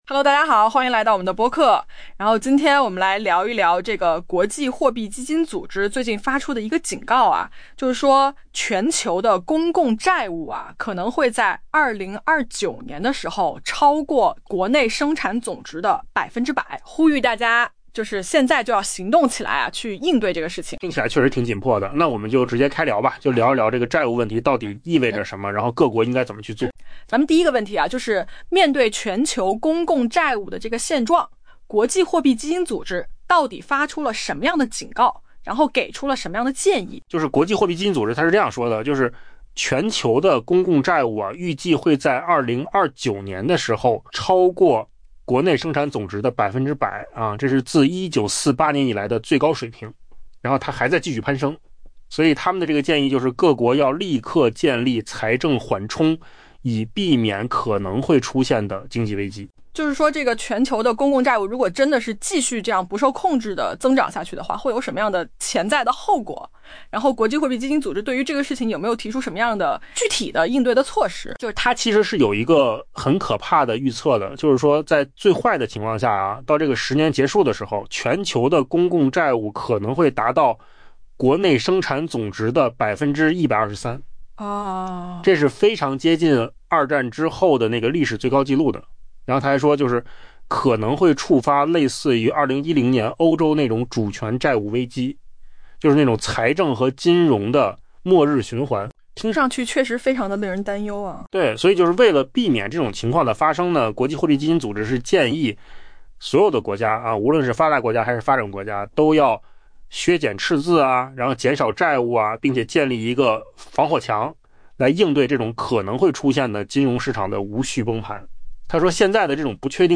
AI 播客：换个方式听新闻 下载 mp3 音频由扣子空间生成 国际货币基金组织 （IMF） 周三发出严峻警告：全球公共债务预计将在 2029 年突破国内生产总值 （GDP） 的 100%， 创下自 1948 年以来的历史新高，并且仍在继续向上攀升。